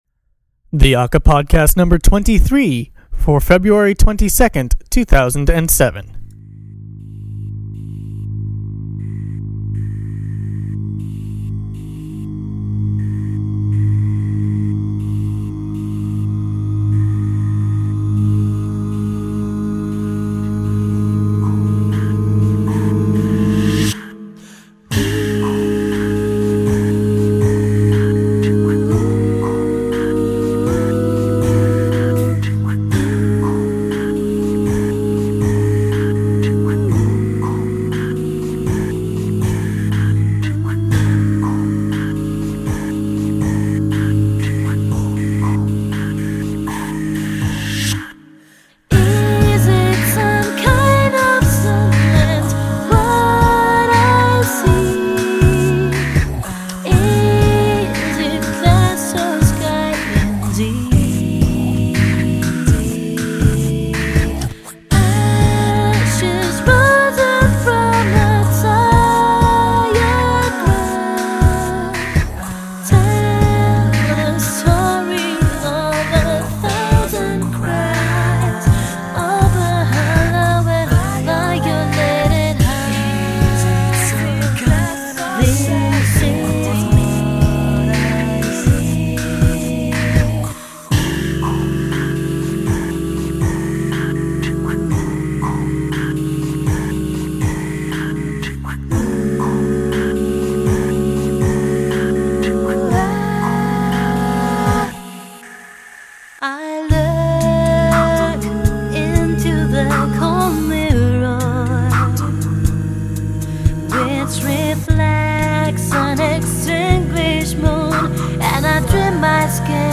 It turns out that good a cappella chops make for good competition chops on thi show.